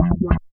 80 BASS LK-R.wav